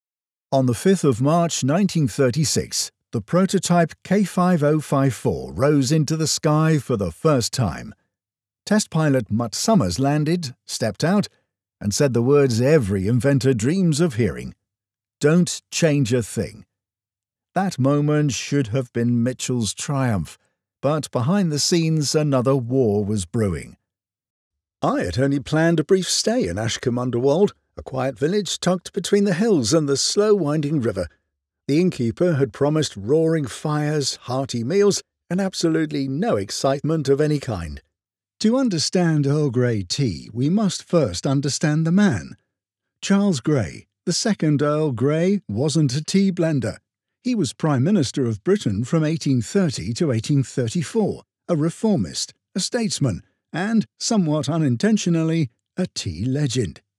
Older Sound (50+)
With over a decade of experience, I have a warm, trustworthy, and versatile British male voice with a natural RP accent.
Broadcast-quality audio from a professional home studio with fast turnaround and seamless delivery.
Audiobooks
Non Fiction - Fiction
0119British_Male_Audiobook_Demo.mp3